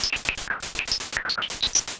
Index of /musicradar/rhythmic-inspiration-samples/120bpm
RI_RhythNoise_120-01.wav